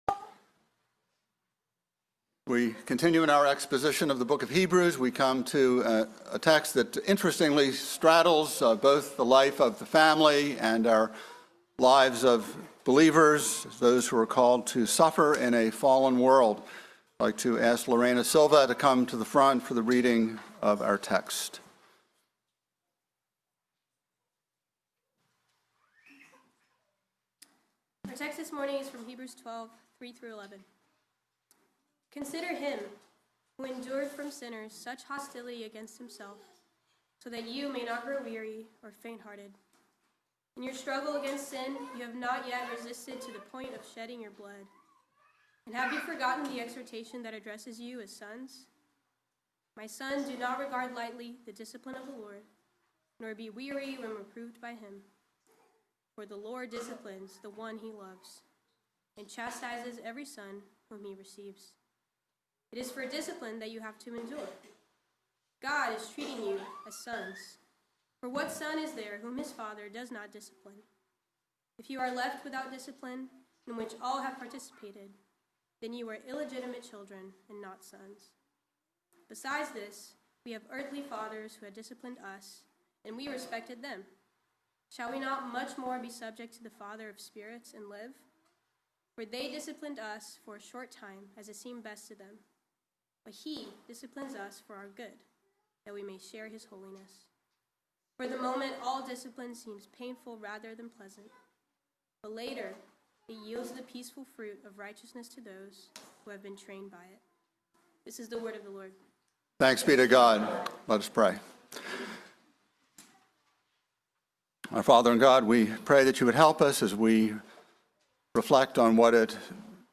by Trinity Presbyterian Church | Mar 27, 2026 | Sermon